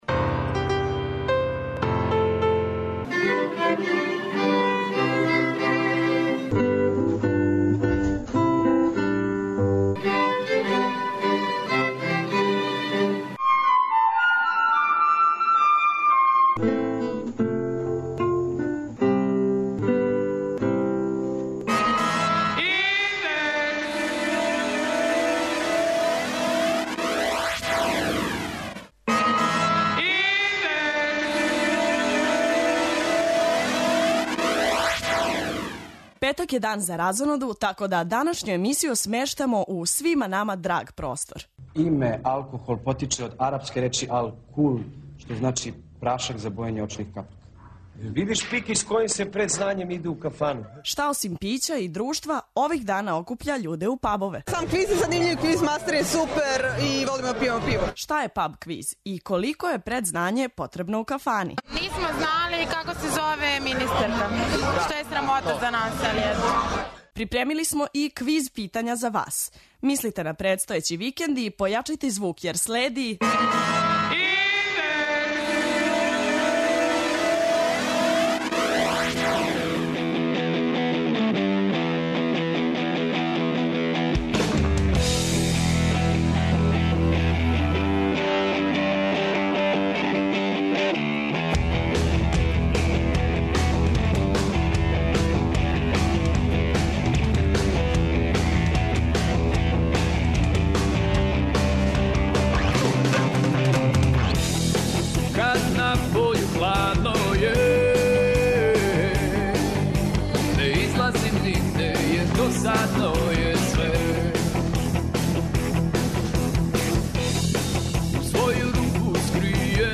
У данашњој емисији говоримо о витешким борбама екипа у знању из опште културе и преносимо вам сјајну атмосферу са једног од дружења на ,,Паб квизу" .
преузми : 18.57 MB Индекс Autor: Београд 202 ''Индекс'' је динамична студентска емисија коју реализују најмлађи новинари Двестадвојке.